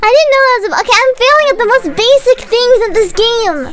Worms speechbanks
Firstblood.wav